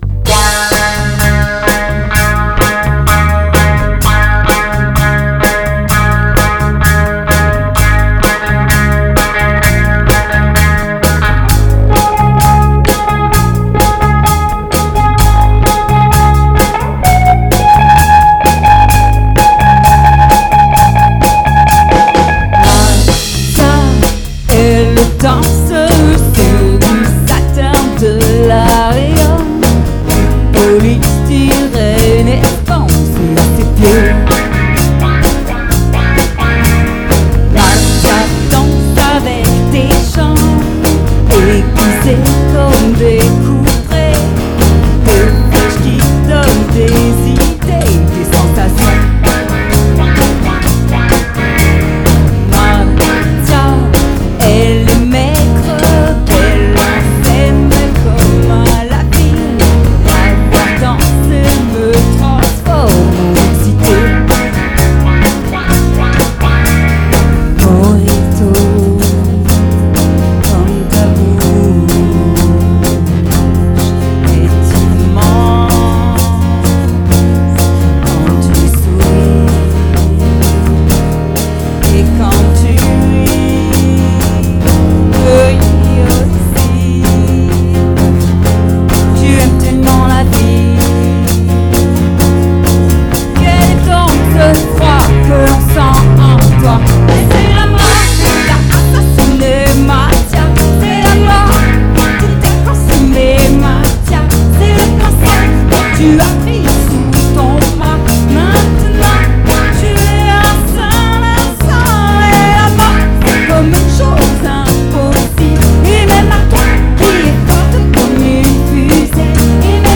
Enregistrement sur scène ou en répétition